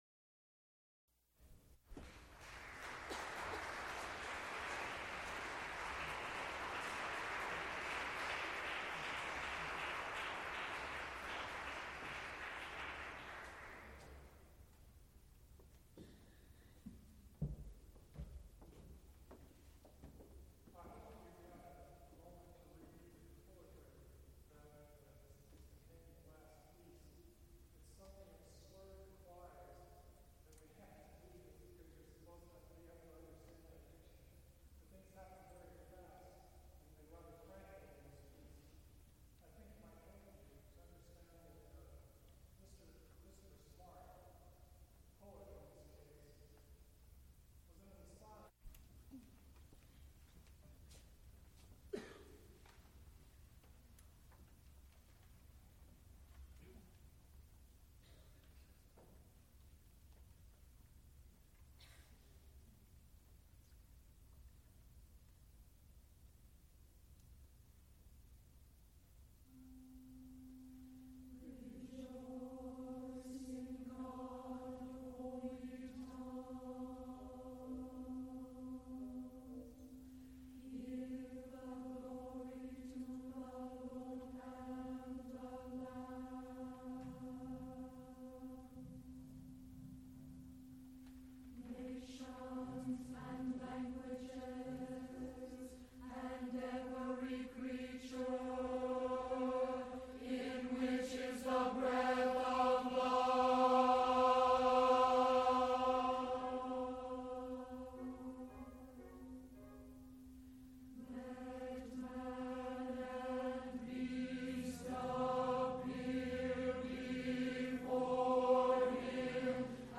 Choruses, Sacred (Mixed voices, 4 parts) with organ
Cantatas, Sacred
soprano
alto
tenor
baritone
Recorded live December 1, 1977, Heinz Chapel, University of Pittsburgh.
Type Sound, musical performances